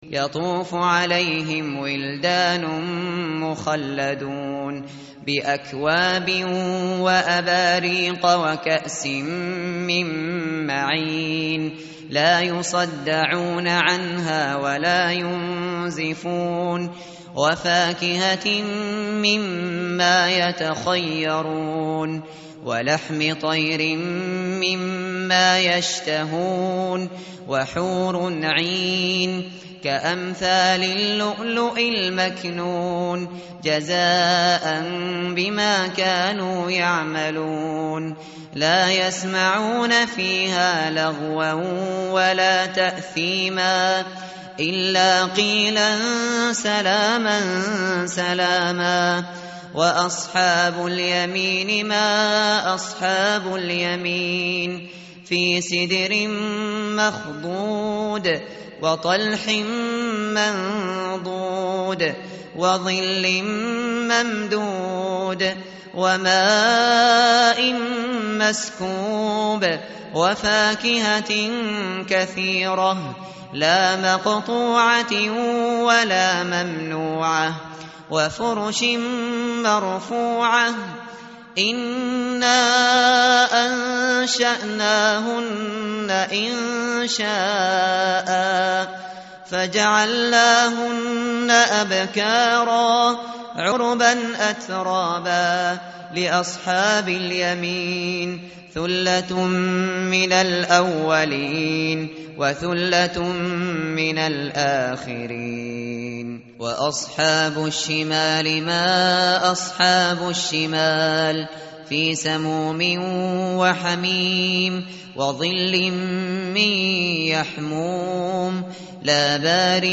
متن قرآن همراه باتلاوت قرآن و ترجمه
tartil_shateri_page_535.mp3